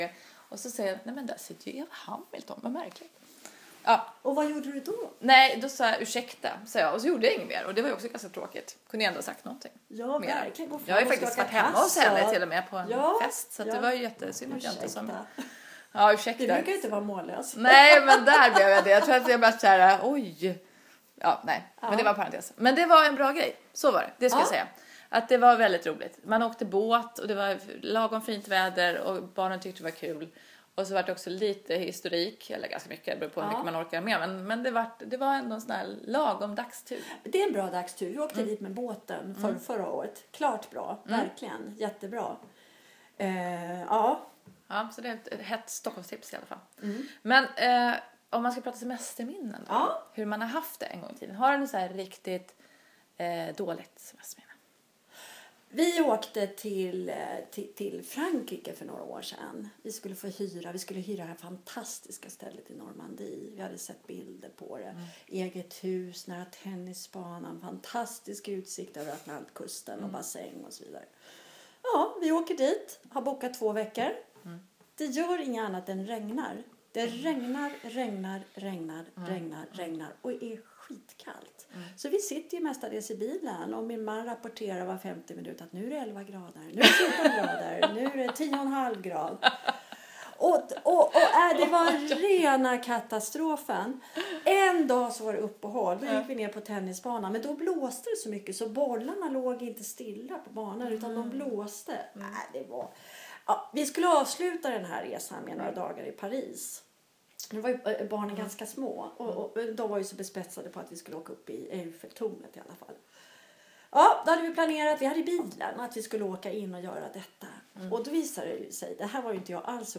Renews redaktörer pratar ledighet inför stundande semester.